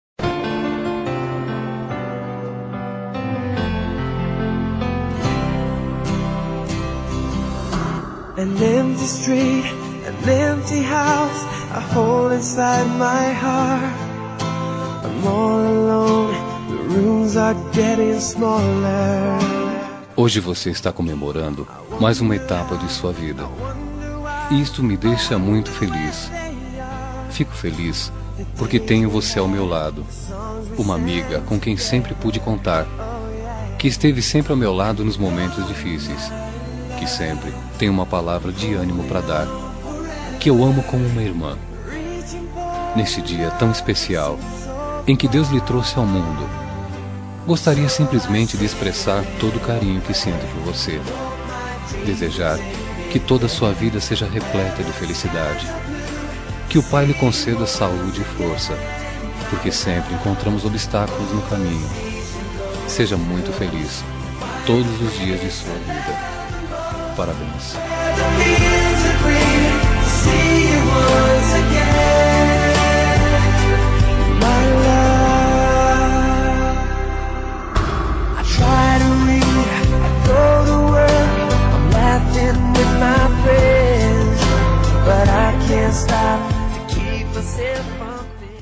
Telemensagem Aniversário de Amiga – Voz Masculina – Cód: 1587 – Como Irmã
617N-70 Aniversário Amiga como irmã- Masculina.MP3